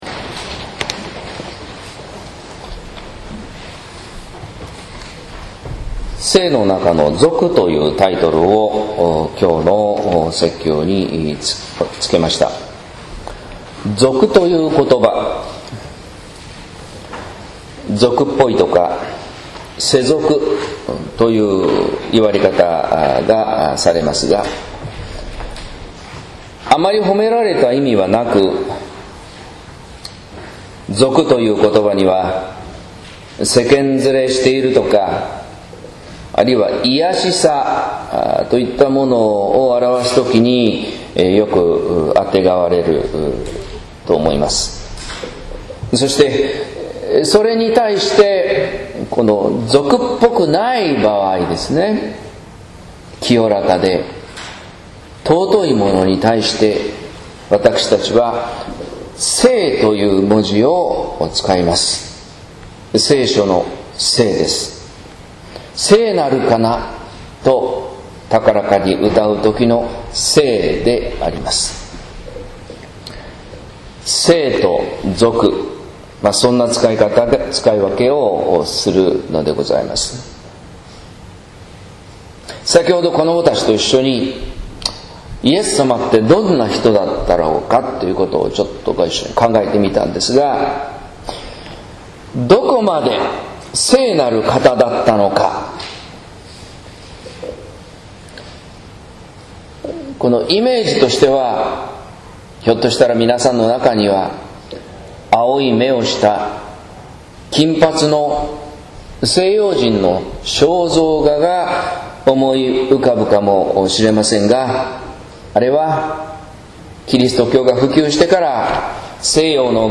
説教「聖の中の俗」（音声版）